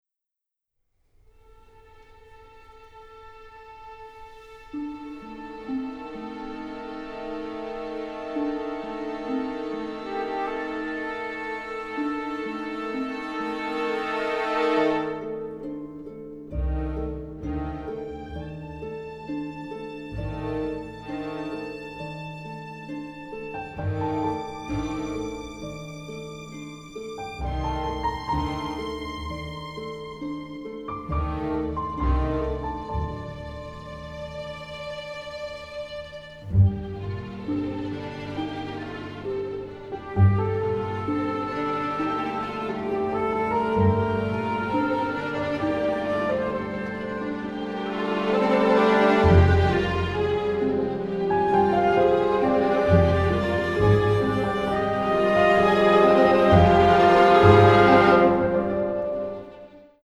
The score has been recorded in London